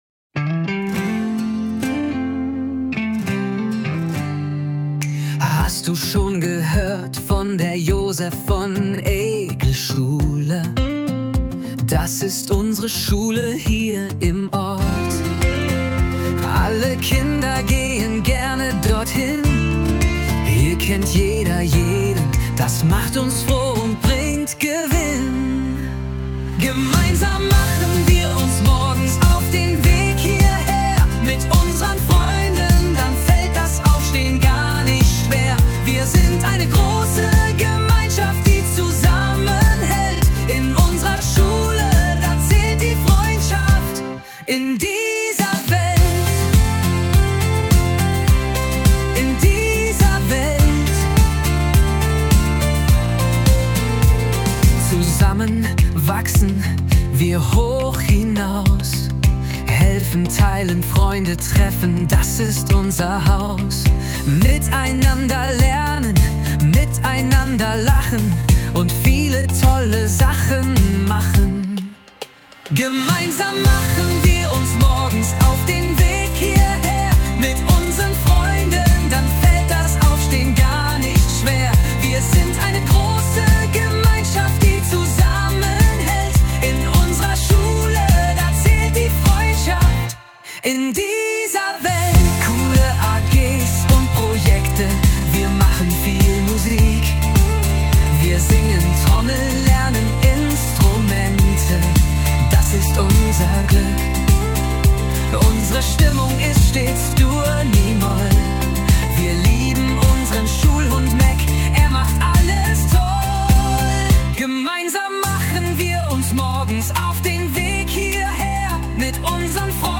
So entstand ein mitreißendes Musikstück, das unsere Schulgemeinschaft klangvoll widerspiegelt.